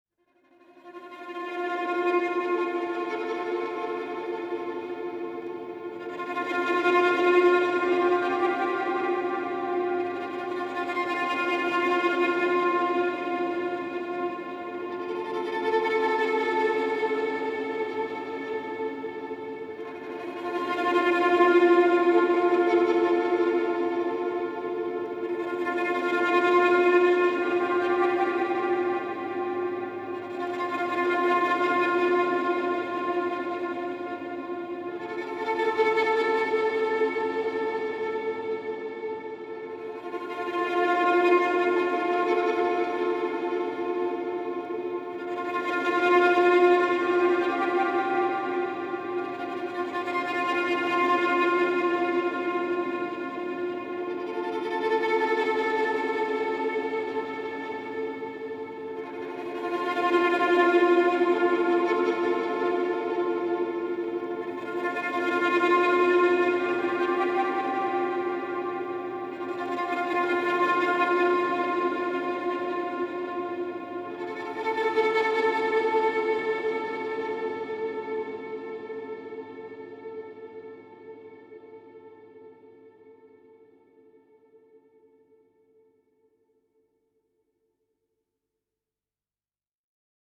Shimmering String Drone Version of STR 065 011.